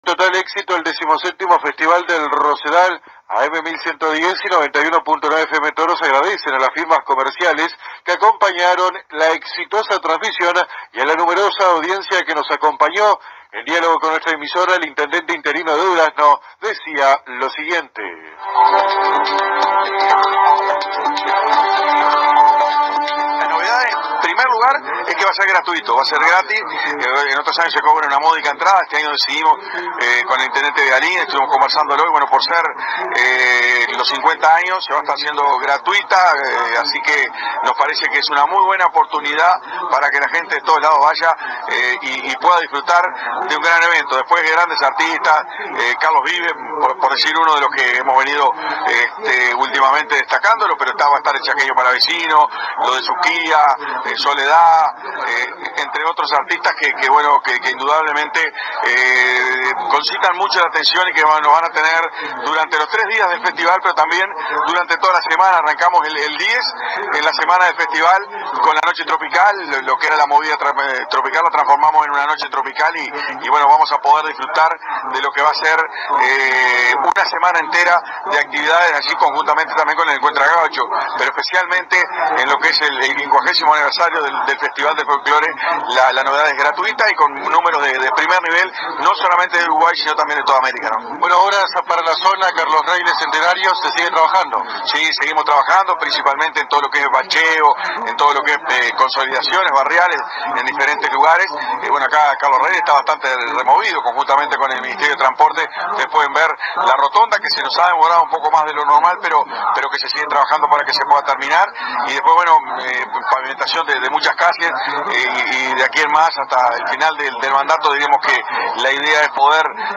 En el marco de la 17ma. Feria del Rosedal que se realizó este fin de semana en la localidad de Carlos Reyles, el Intendente interino de Durazno, Domingo Rielli conversó con los colegas de AM 1110 de nuestra ciudad, sobre diversos temas que atañen a la colectividad duraznenese y de la región central.